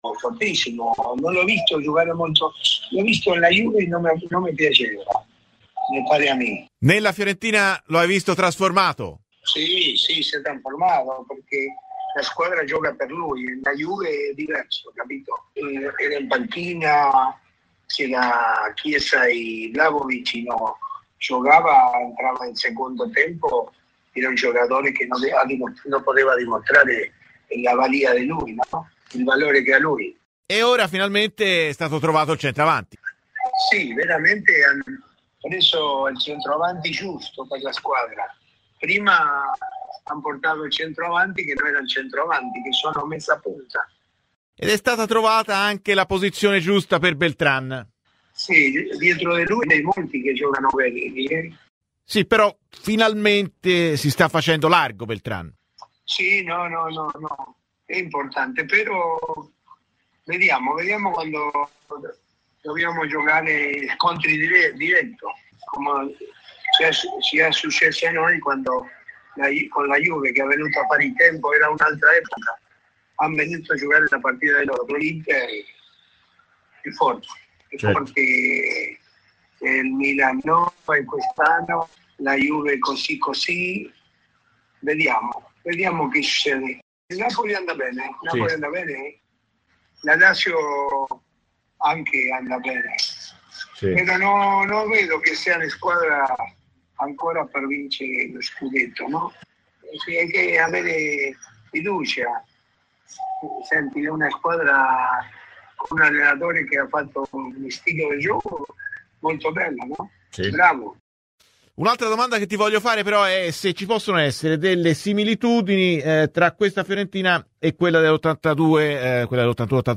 L'ex viola Daniel Bertoni ha parlato oggi a Radio Firenzeviola, durante 'Viola amore mio', partendo da un commento su Moise Kean: "Lo avevo visto nella Juventus e non mi piaceva, ora invece è trasformato perché la squadra gioca per lui. In bianconero aveva Chiesa e Vlahovic davanti. La Fiorentina ha trovato finalmente il centravanti".